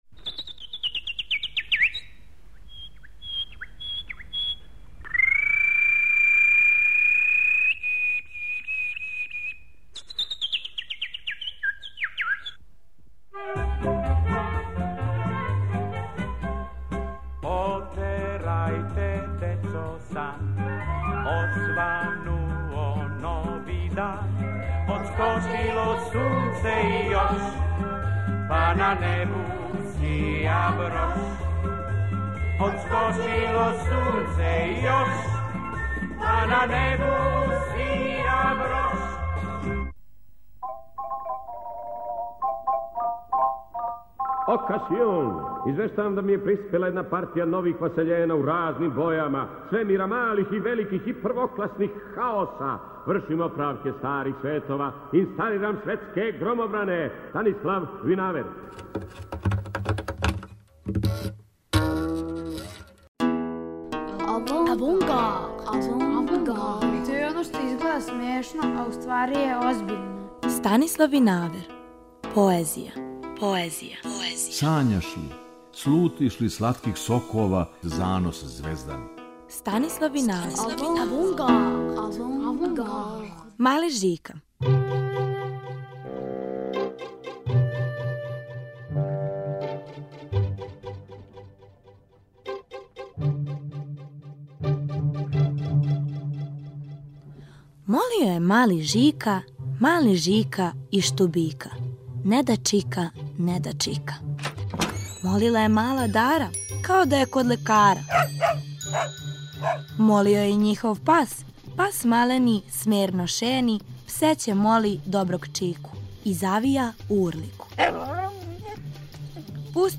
Поезија, Станислав Винавер